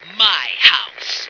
flak_m/sounds/female2/int/F2myhouse.ogg at 86e4571f7d968cc283817f5db8ed1df173ad3393